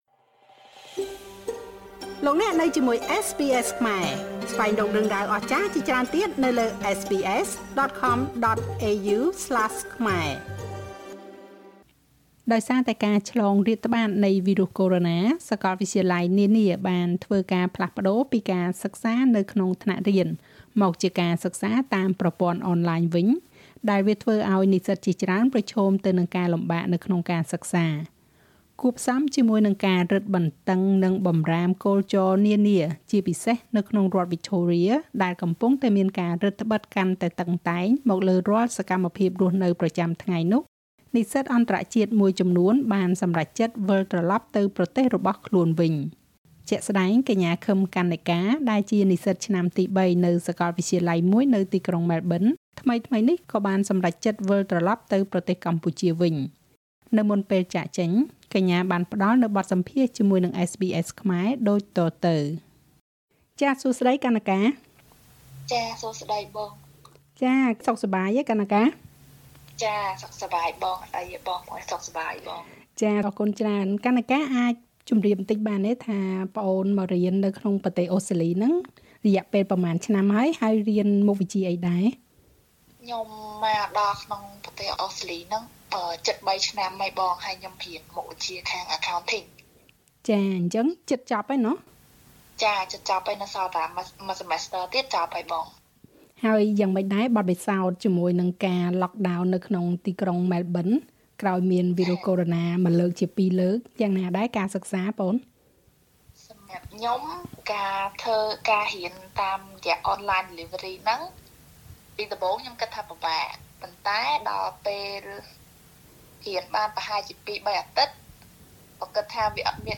នៅមុនពេលចាកចេញ កញ្ញាបានផ្តល់នូវបទសម្ភាសន៍ជាមួយ SBSខ្មែរ ដូចតទៅនេះ។